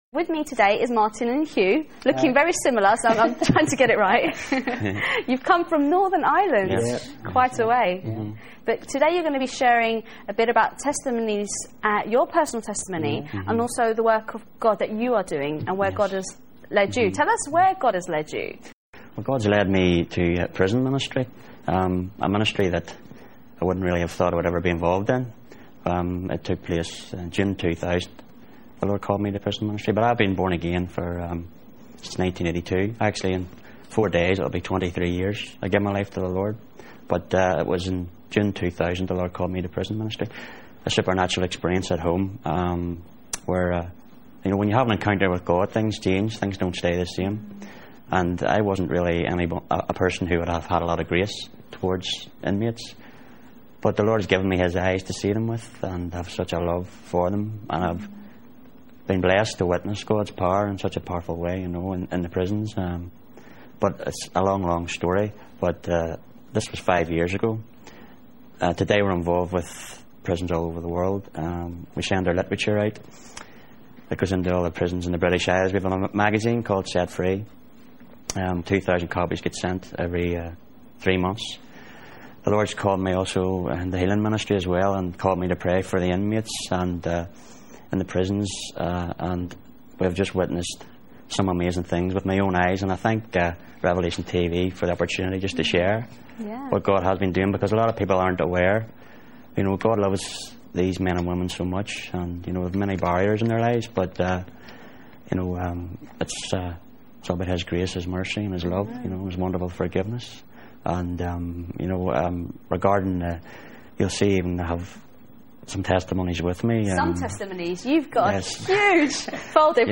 Revelation TV interviews: "World in Focus" + "R-Mornings" (77 mins., 2005) 2.